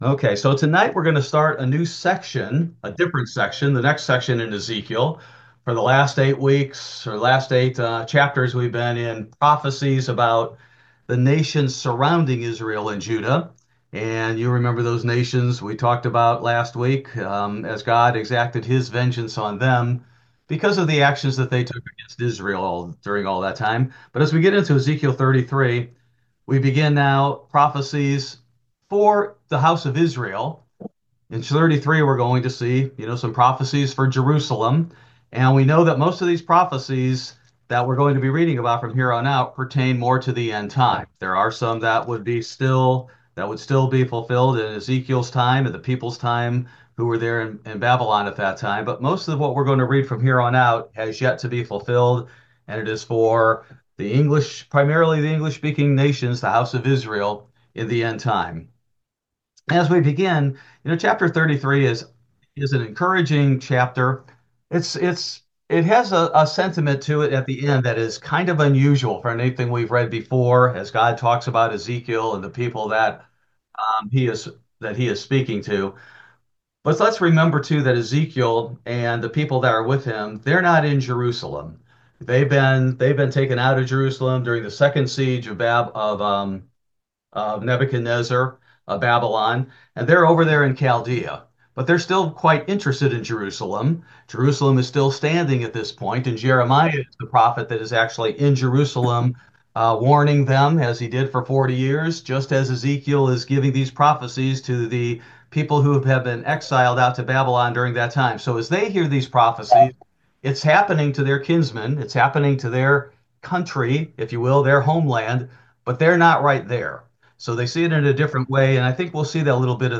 Ezekiel Bible Study: January 29, 2025